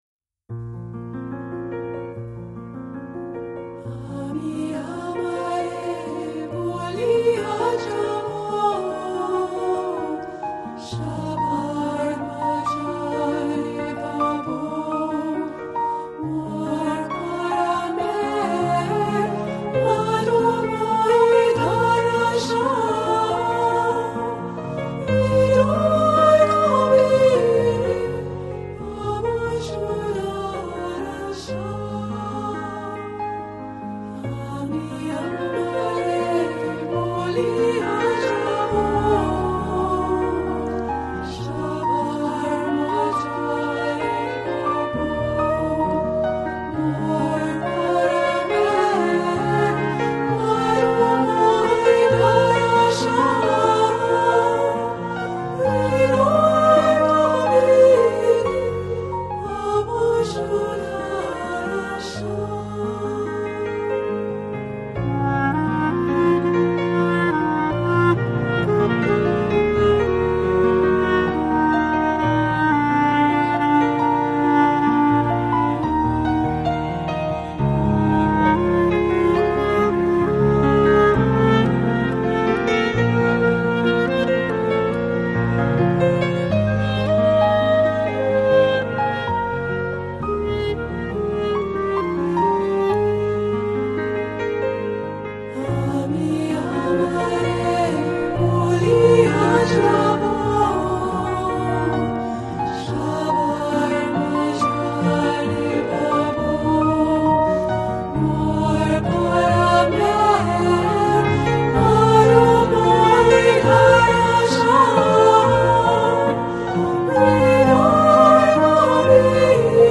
Жанр: World, Meditative